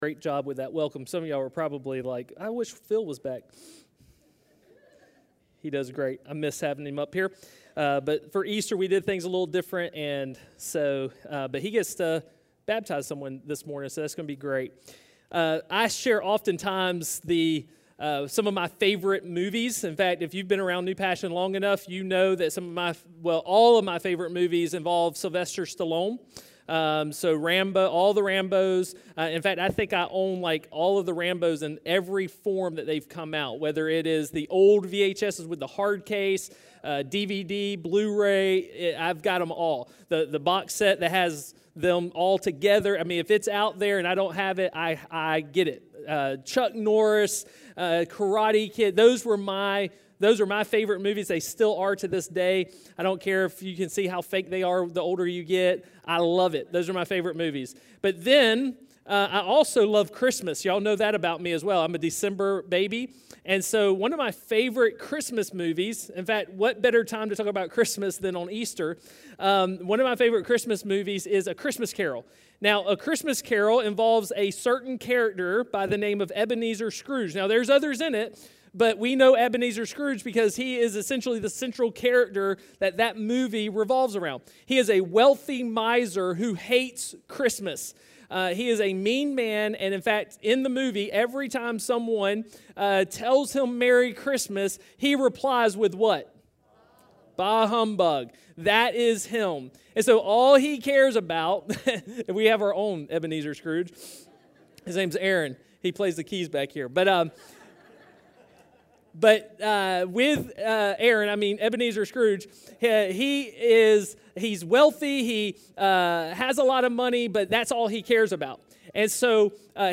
A stand alone sermoin on getting back on the right path with Jesus.
Podcast-New-Beginnings-Easter-Service.mp3